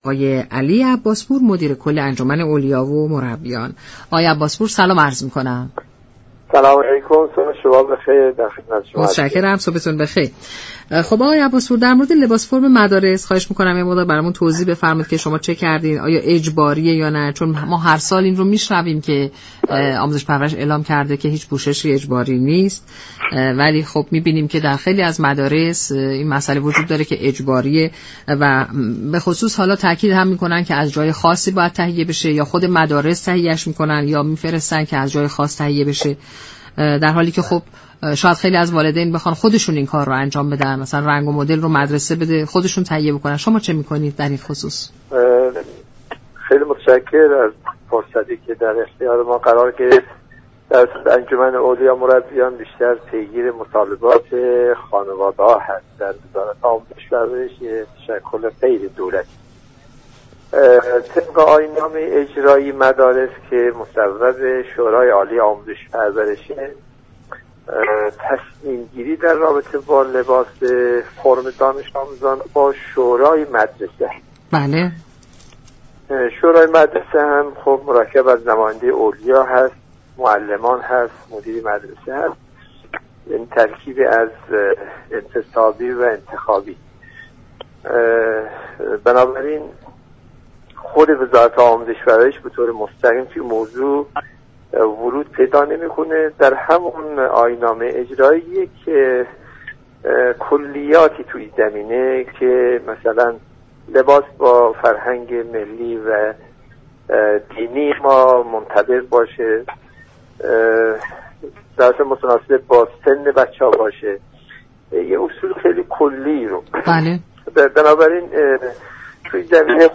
علی عباس پور مدیر كل انجمن اولیا و مربیان وزارت آموزش و پرورش در گفت و گو با برنامه «سلام صبح بخیر» گفت: وزارت آموزش و پرورش در انتخاب لباس فرم هماهنگ دانش آموزان دخالت مستقیم ندارد.